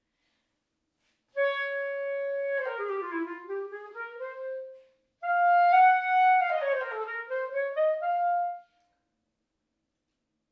on modern clarinet